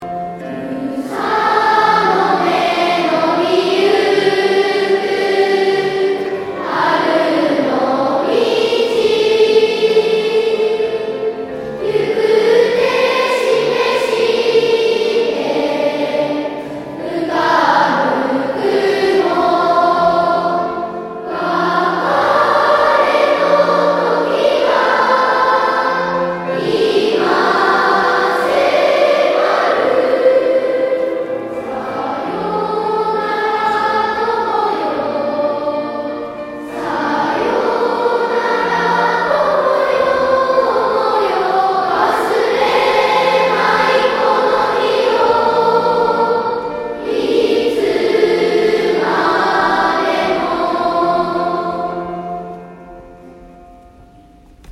全校生で合わせるのは今日が初めてです。
何回か歌う内にとても上手になってきました。